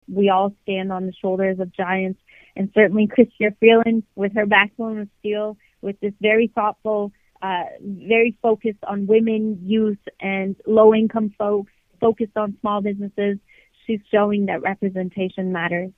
myFM spoke with Minister for Women and Gender Equality, Maryam Monsef, about the occasion.